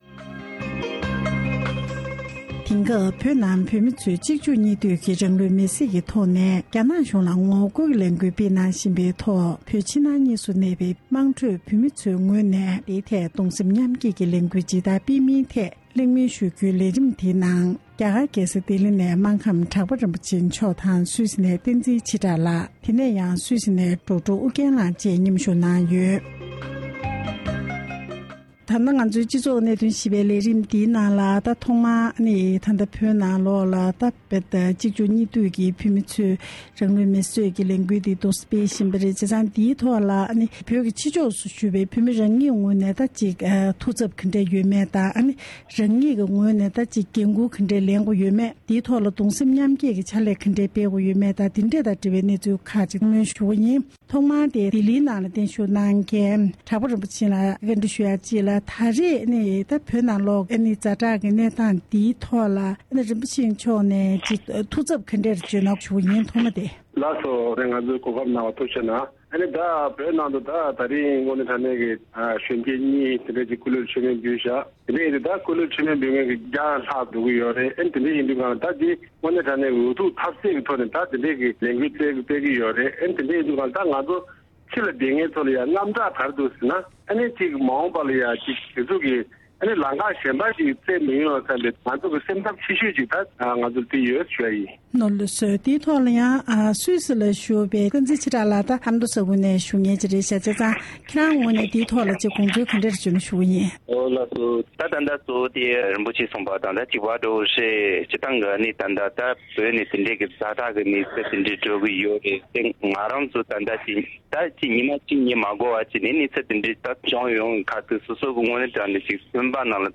འབྲེལ་ཡོད་དང་གླེང་མོལ་ཞུས་པར་གསན་རོགས་གནང་།།